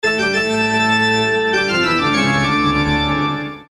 орган
эпичные